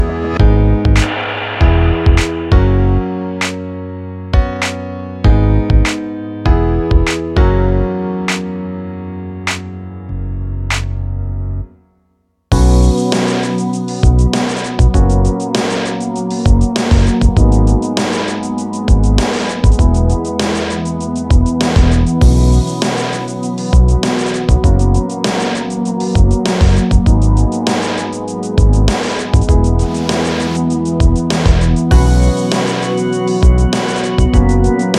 Жанр: Поп музыка / Соундтрэки
Soundtrack, Pop, K-Pop